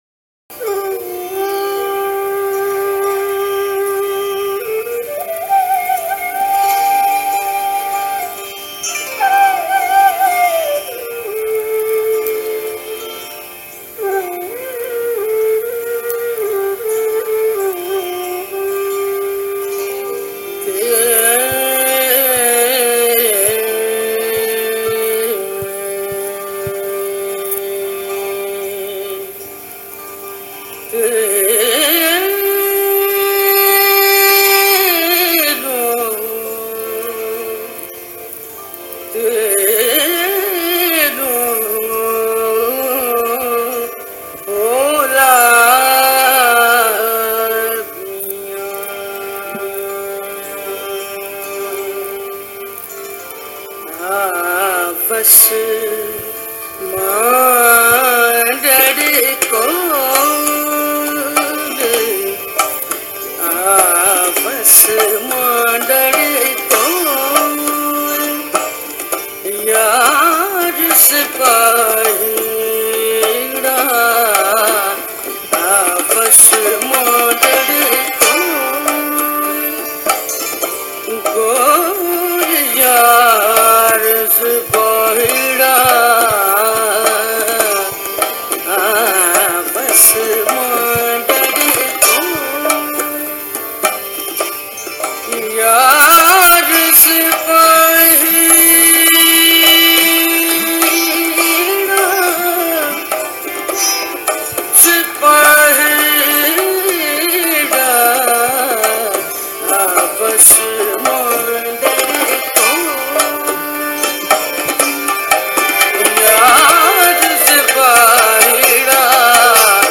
Sufi Songs
Kalaam/Poetry